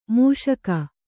♪ mūṣaka